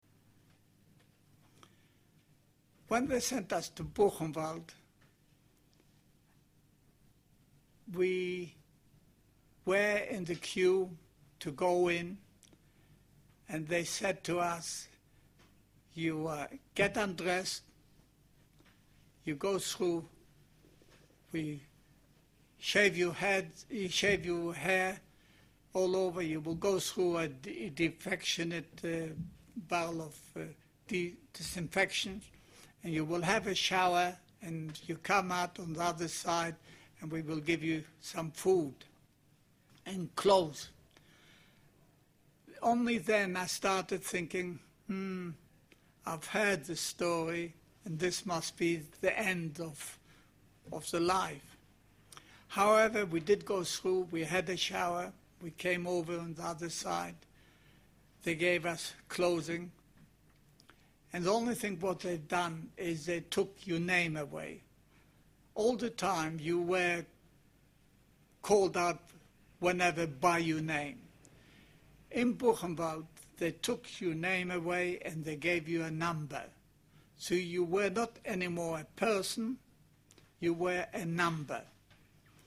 at a Chabad meeting in Oxford in 2019